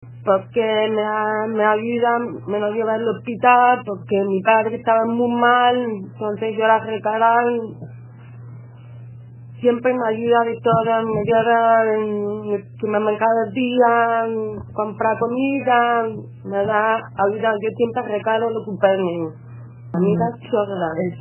con palabras entrecortadas formato MP3 audio(0,40 MB).